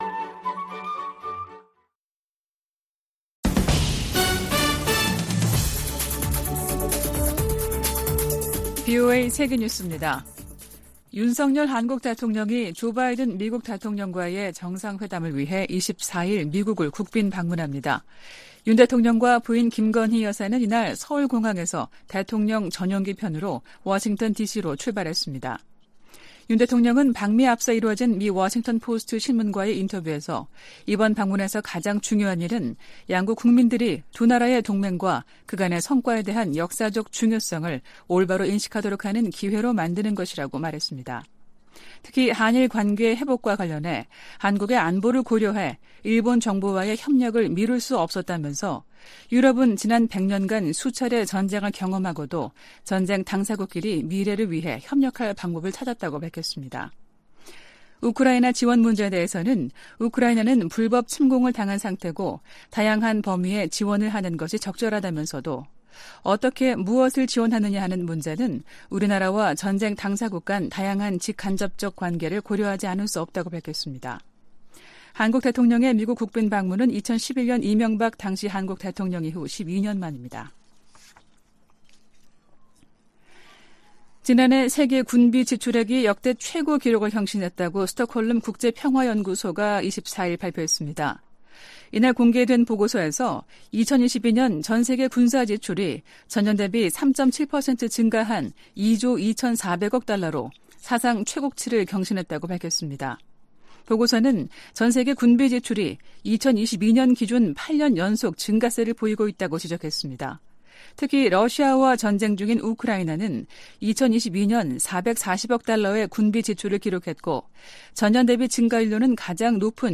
VOA 한국어 아침 뉴스 프로그램 '워싱턴 뉴스 광장' 2023년 4월 25일 방송입니다. 백악관은 윤석열 한국 대통령의 국빈 방문이 미한 관계의 중요성을 증명하는 것이라고 강조했습니다. 윤 대통령은 방미를 앞두고 워싱턴포스트 인터뷰에서 미한동맹의 중요성을 거듭 강조했습니다. 미 국무부가 핵보유국 지위와 관련한 북한의 주장에 대해 불안정을 조성하는 행동을 자제하고 협상에 복귀할 것을 촉구했습니다.